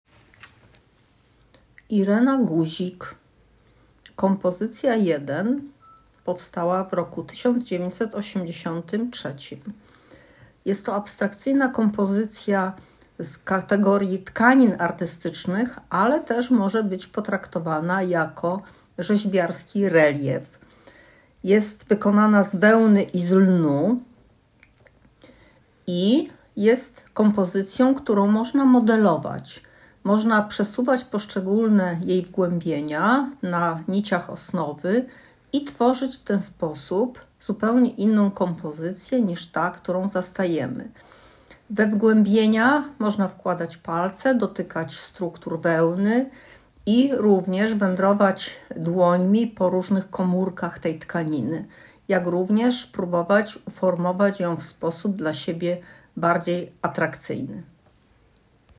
Kategoria: Kolekcja BWA, PRACE Z AUDIODESKRYPCJĄ